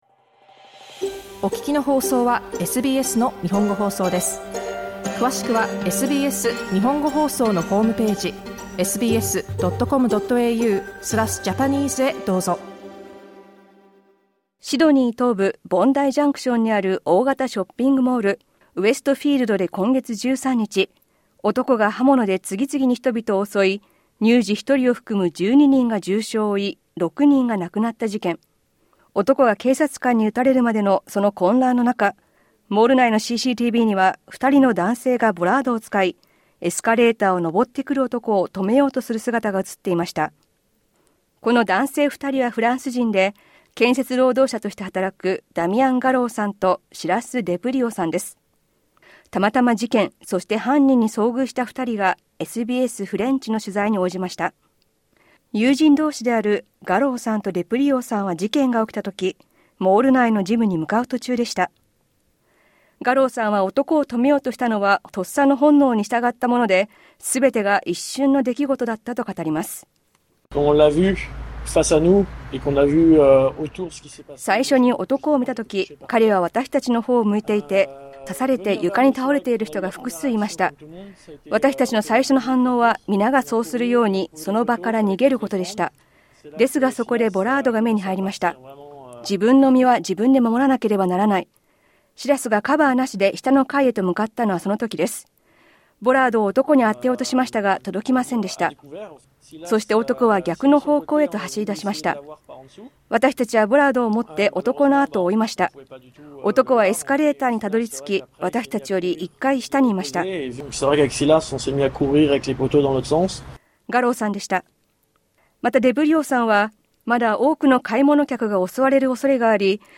「一瞬の出来事」が多くの人や自分たちの人生、そしてこれからの生き方に大きな影響を与えました。犯人に立ち向かったフランス人男性２人が SBS French の取材に応じました。